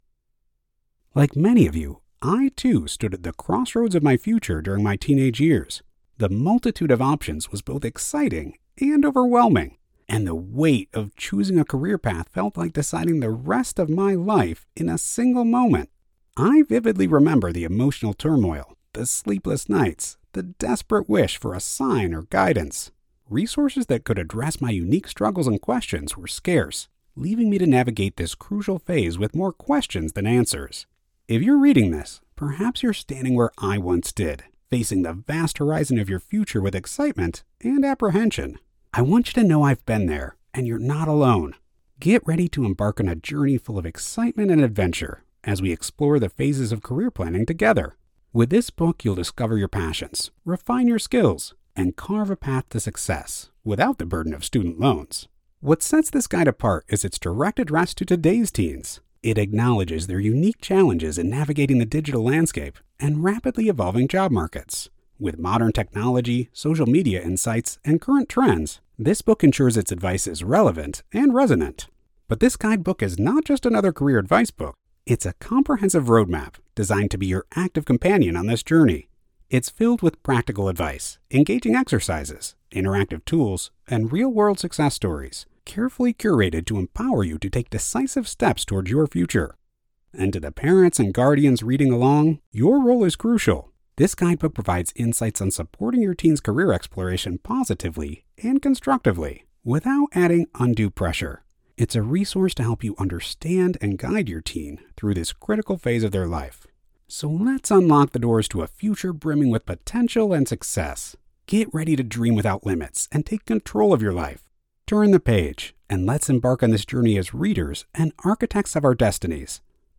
The warm, confident, intelligent voice of the guy next door
Audiobook, Young Adult Nonfiction: Career Planning for Teens
Midwestern / Neutral
Middle Aged